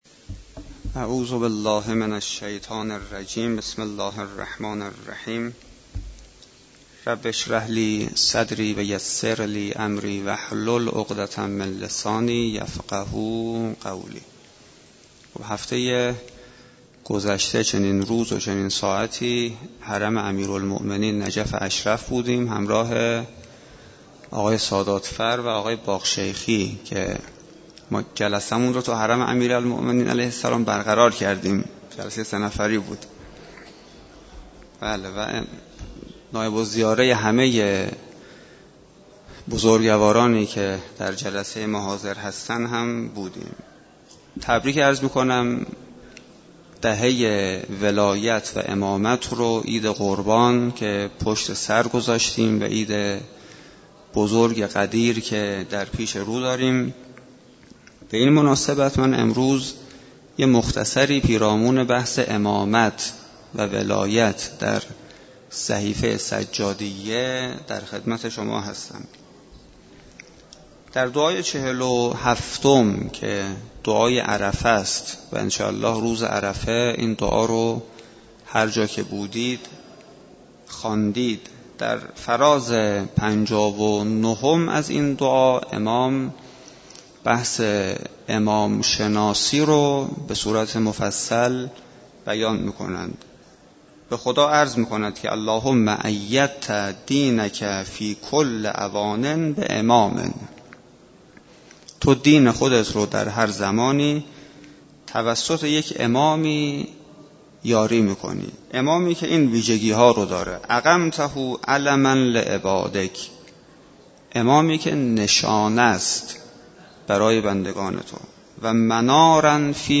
سخنرانی
به مناسبت دهه امامت و ولایت و عید خجسته و بزرگ غدیر در مسجد دانشگاه کاشان